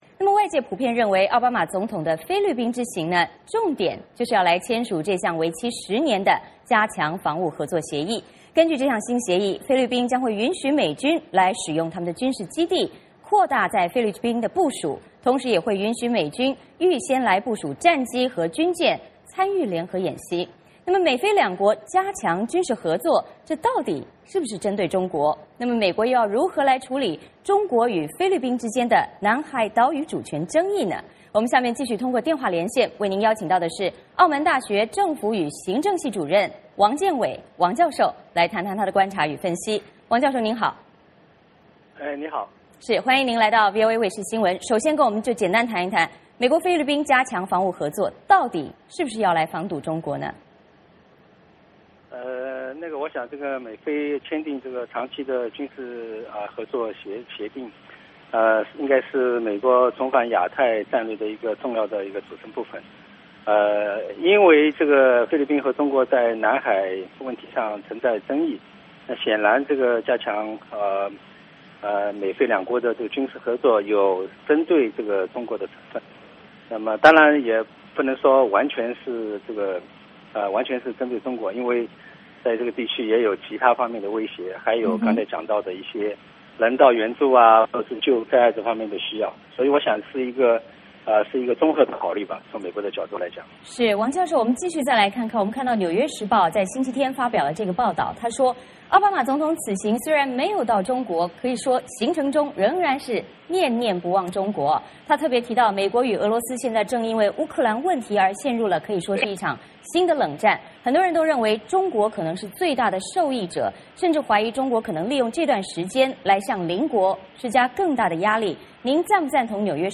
VOA连线：奥巴马抵菲，两国加强防务合作所为何来？